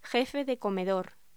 Locución: Jefe de comedor
voz